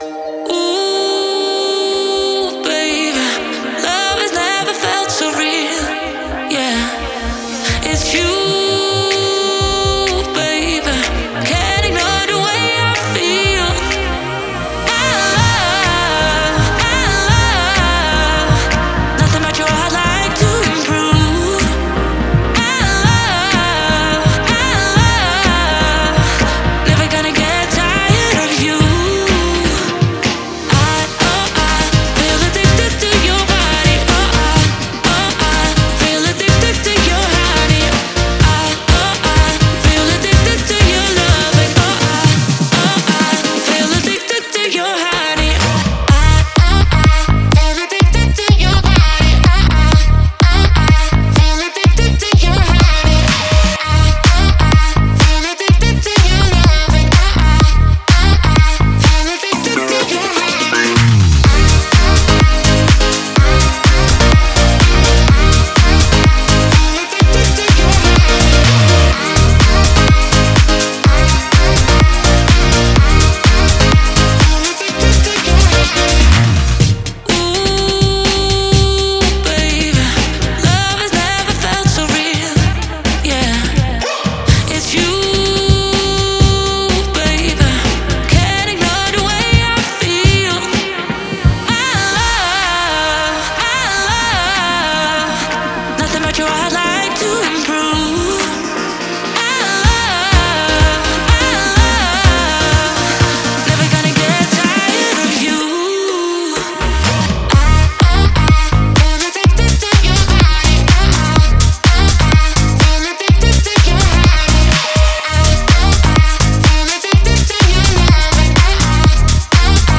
это нежная и мелодичная песня в жанре инди-поп